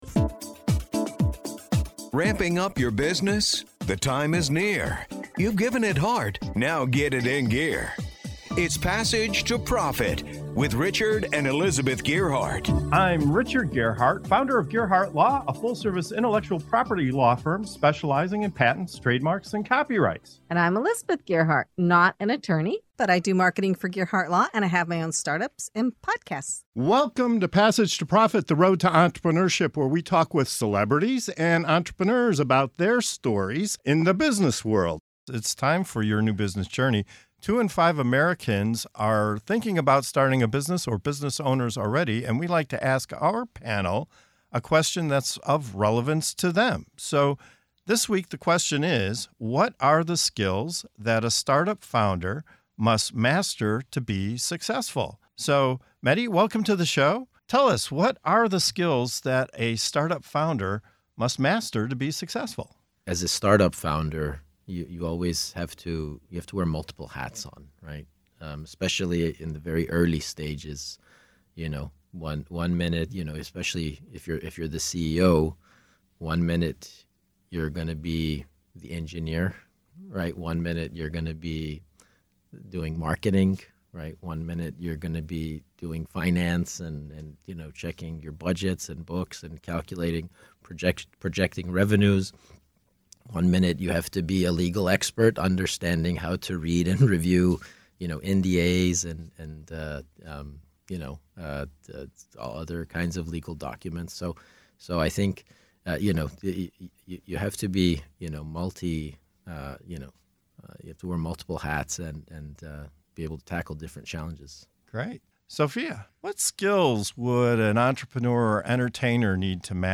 In this segment of "Your New Business Journey" on Passage to Profit Show, our guests dive into the essential skills every startup founder needs—from mastering multiple roles in the early days to building strong, lasting relationships that fuel long-term growth. Whether you’re juggling engineering, marketing, or legal duties, or choosing the right people to bring your vision to life, this conversation is packed with insights for entrepreneurs at every stage.